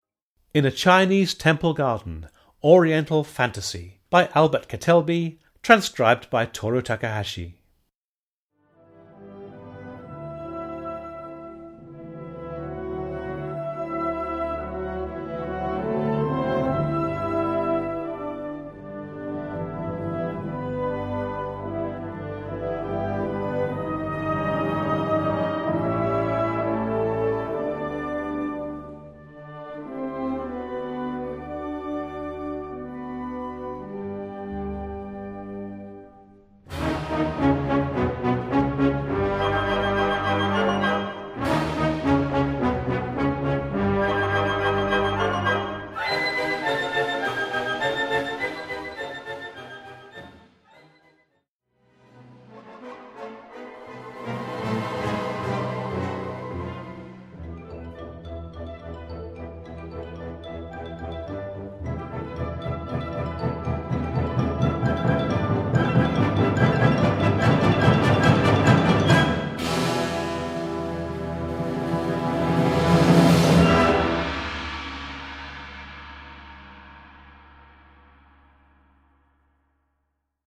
E minor（原調）
男声合唱を加えるとエキゾチックさが増します。